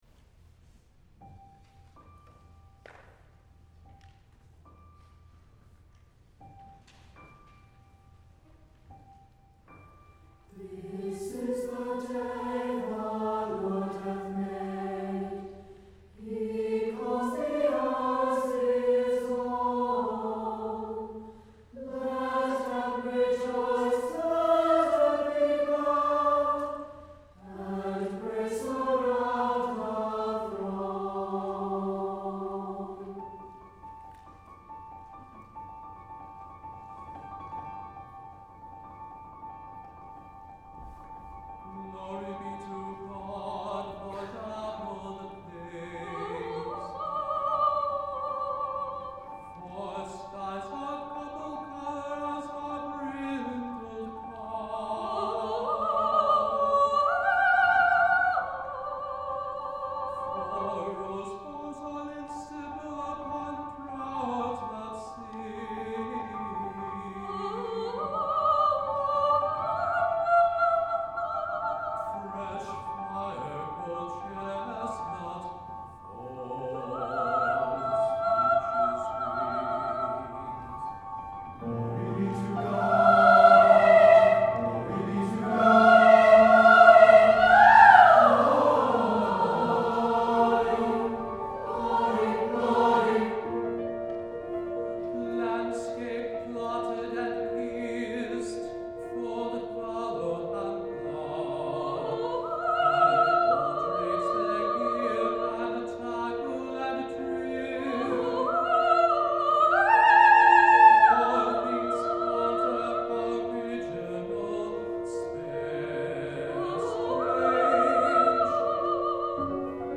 for SATB Chorus, SB Soli, and Piano (1985)